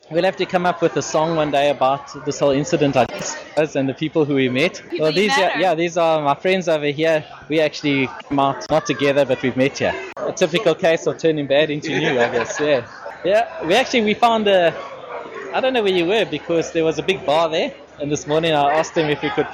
• Male, White
• Pretoria, South Africa
South-African-Pretoria-White-Male-Age-30-EJDD.mp3